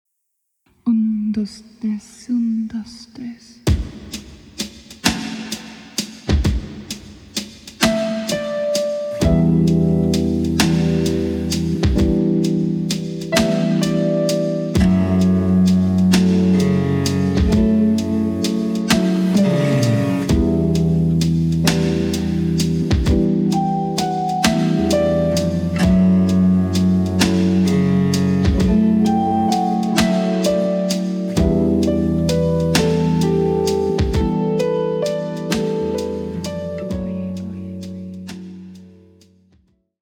Género: New Age / Travel.